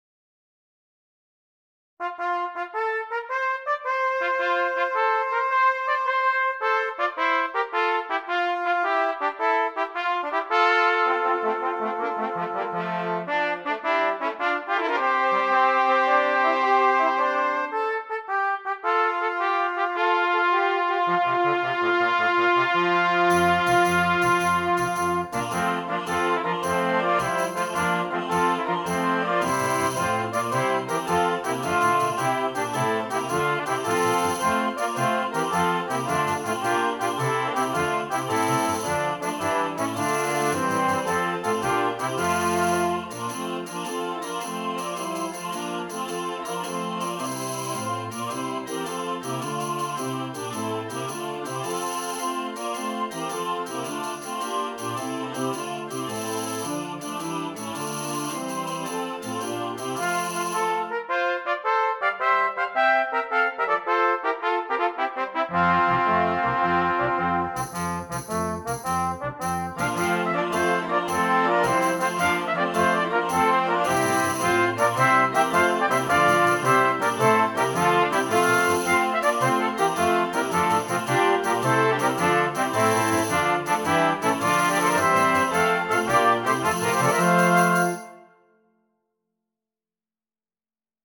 Christmas
Brass Quintet, Organ and Optional Choir
Traditional
a Medieval tune